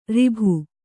♪ ribhu